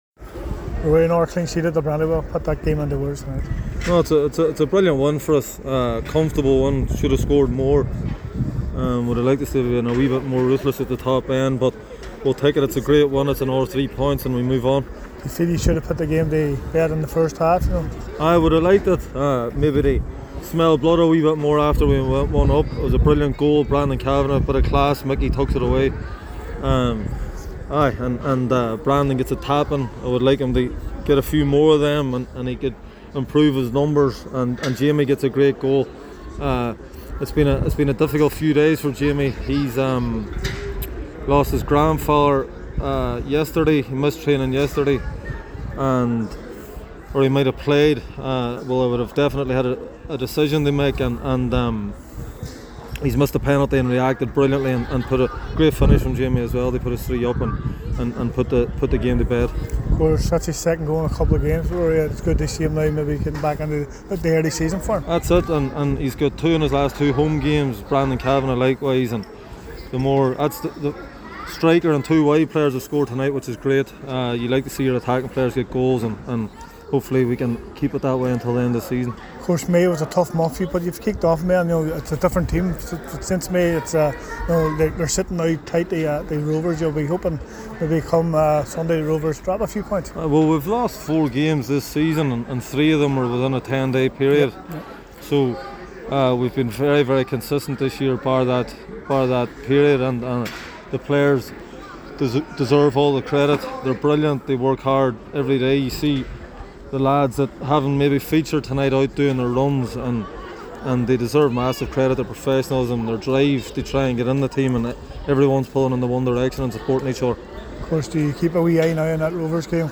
Derry beat Finn Harps 3 nil in North West derby. We hear from Ruaidhri Higgins at the final whistle